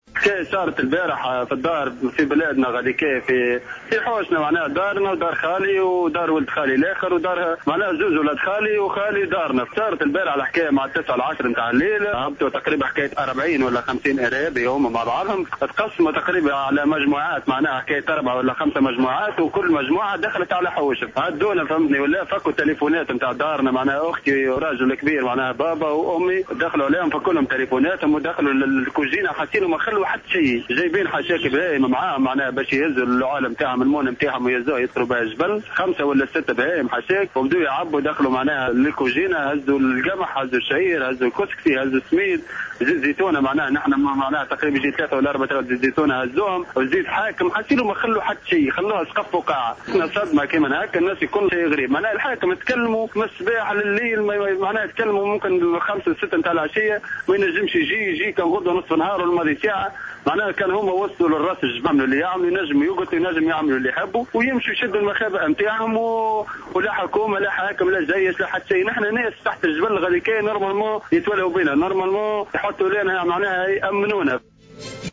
وقال أحد أهالي المنطقة في تصريح ل"الجوهرة أف أم" إن عددا كبيرا من المسلحين داهموا عددا من المنازل و قاموا بالاستيلاء على المؤنة ثم لاذوا بالفرار.